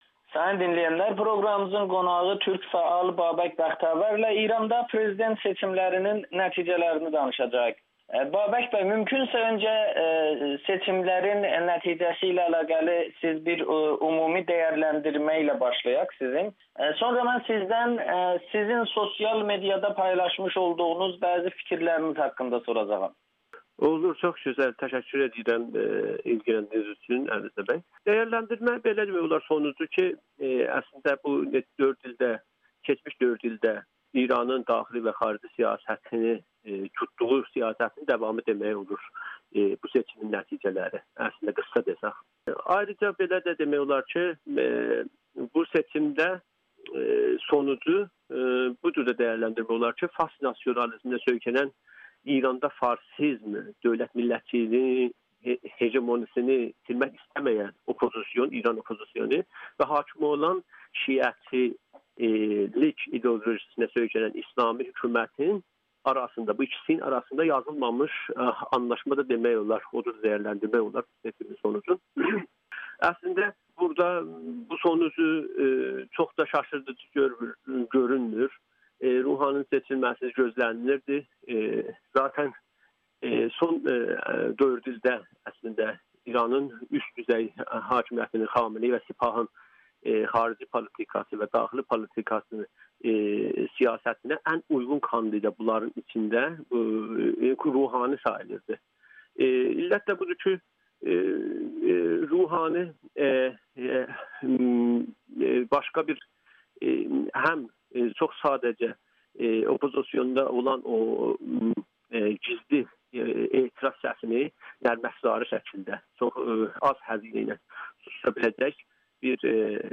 Ruhani İranın indiki xarici siyasətinin davamı üçün seçilməli idi [Audio-Müsahibə]